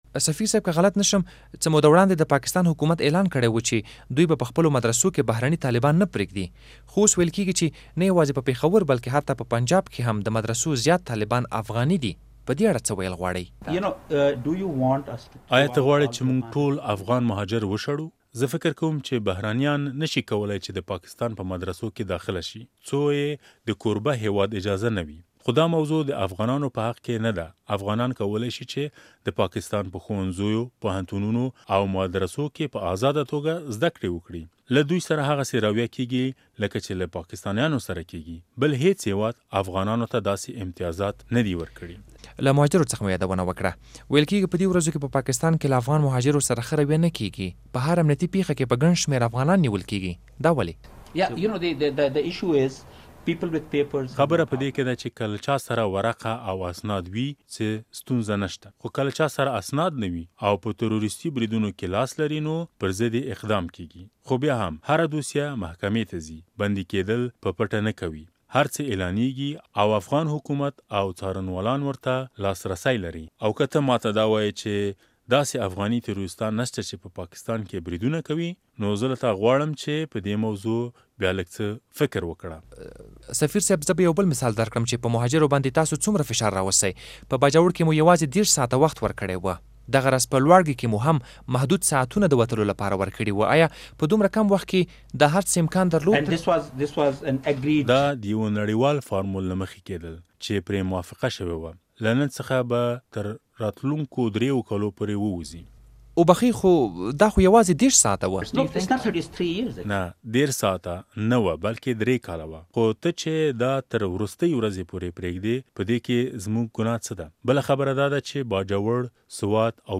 کابل کې د پاکستان له سفیر محمد صادق سره دمرکې دویمه برخه واورﺉ